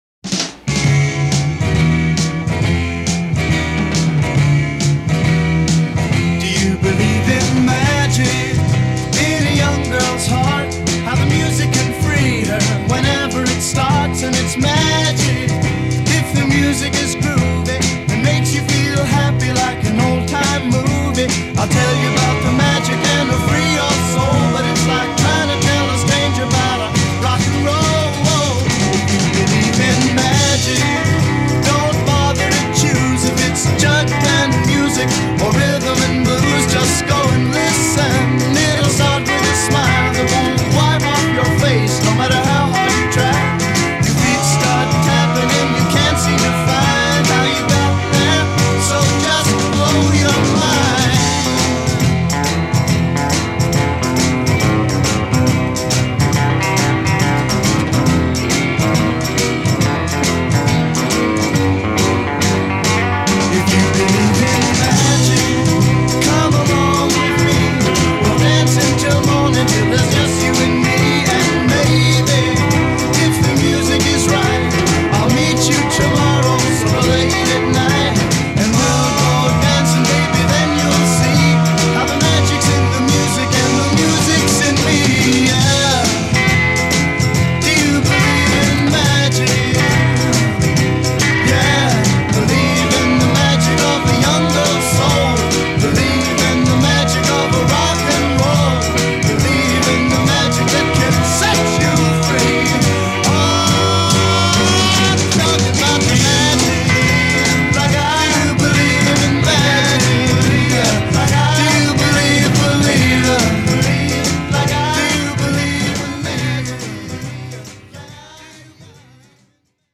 It’s over and done with in 2 minutes.